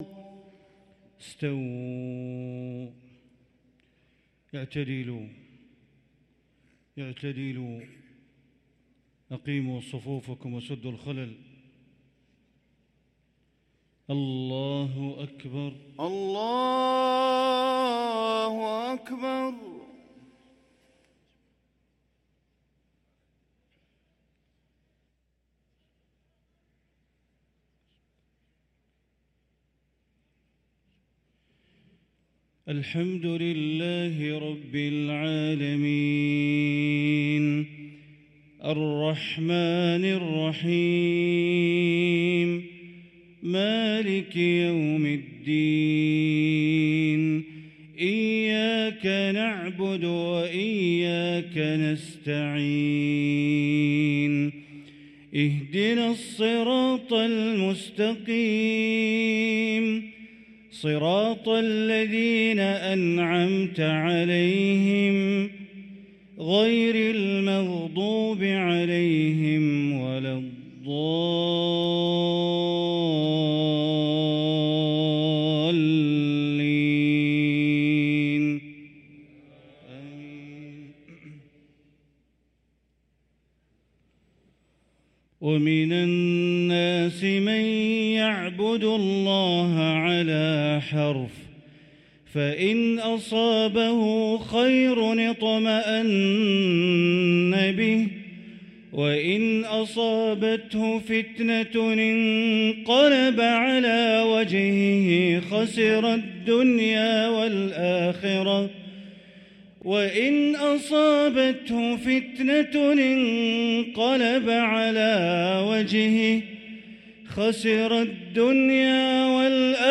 صلاة العشاء للقارئ بندر بليلة 25 ربيع الأول 1445 هـ
تِلَاوَات الْحَرَمَيْن .